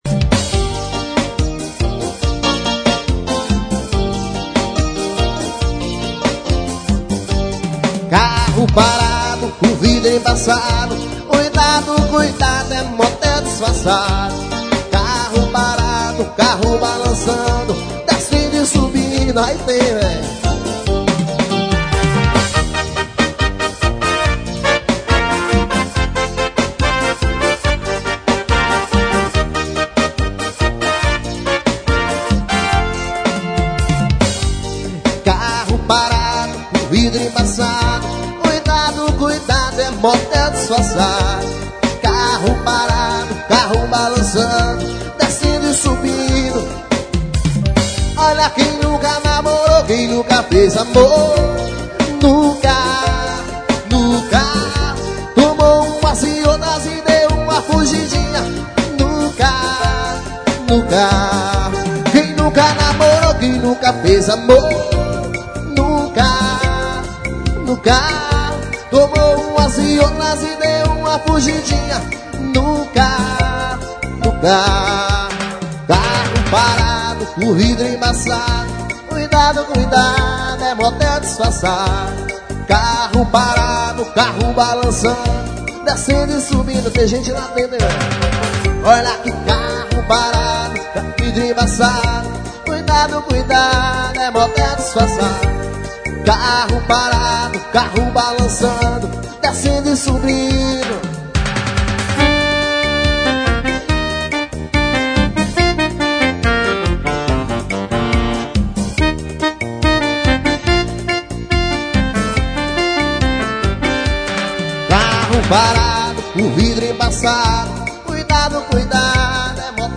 ao vivo no escorrega.